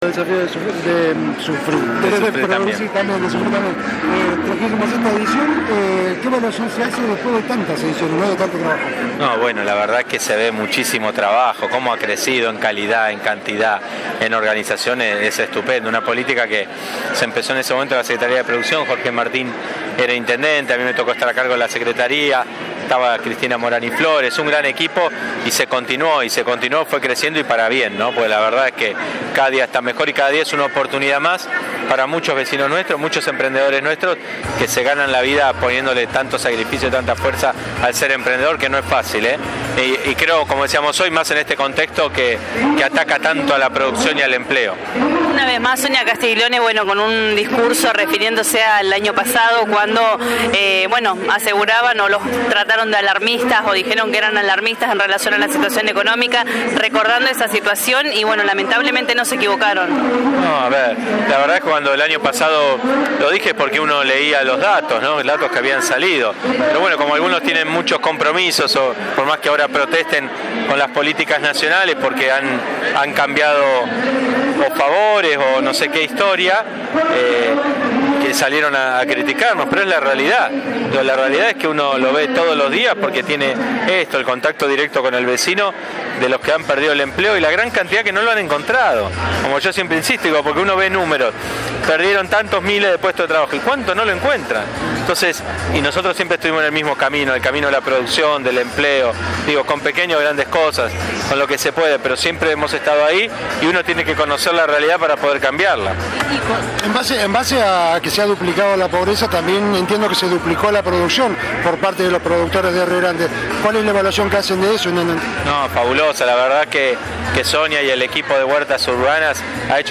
El intendente de Rio Grande destaco el crecimiento y calidad de los productos que se presentaron en la 36a edición de El Desafió de Producir» que se inauguró hoy y recordó también que cuando se anuncio el aumento de la pobreza y el desempleo, no se equivocaron porque tenemos contacto con el vecino, sabemos lo que les cuesta, siempre hemos estado ahí y conocer la realidad para poder cambiarla»